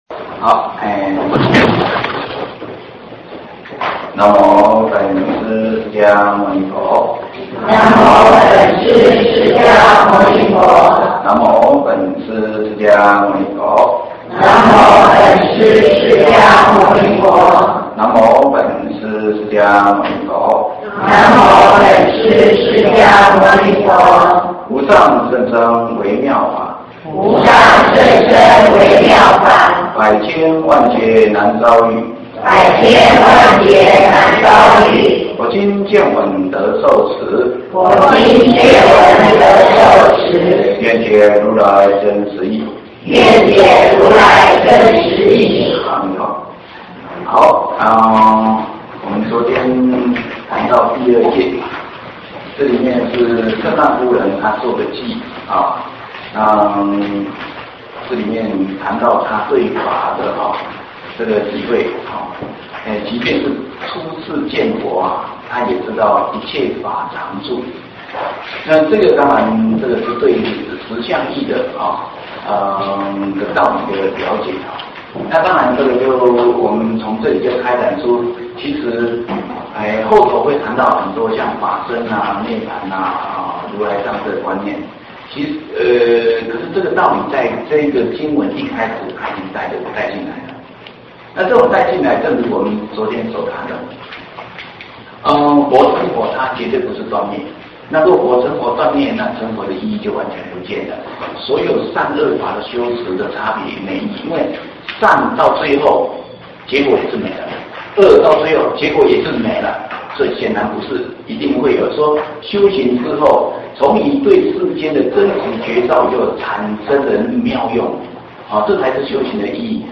41胜鬘经(玉佛寺)